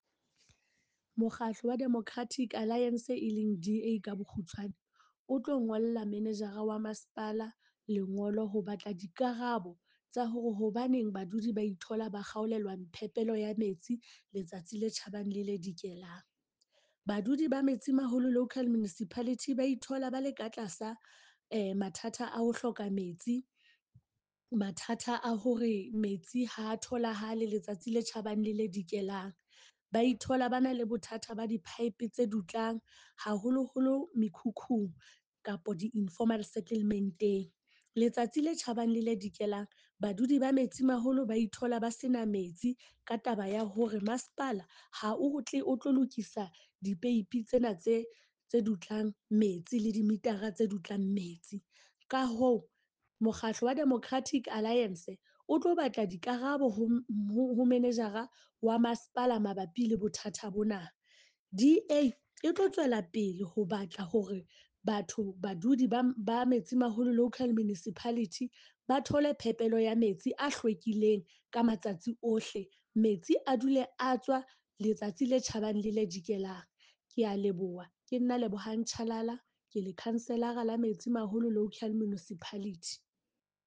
Sesotho soundbite by Cllr Lebohang Chalala.